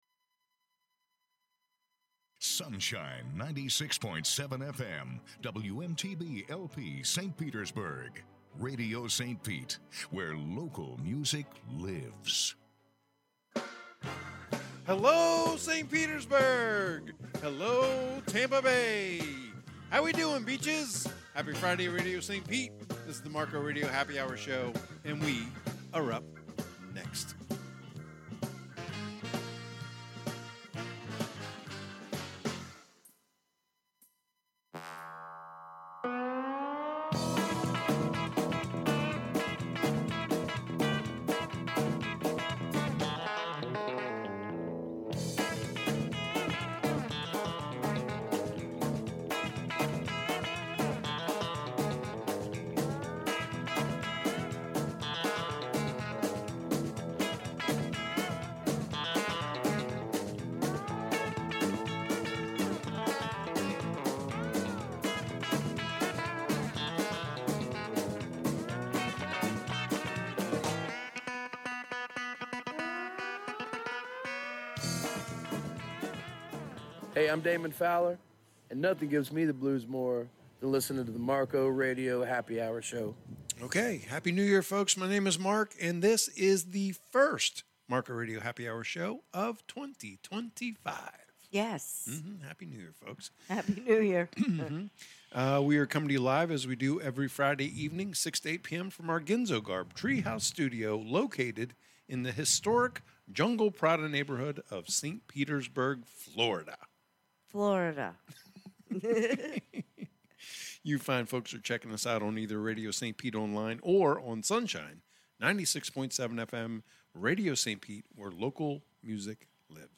Airs live 6pm ET Fridays from Jungle Prada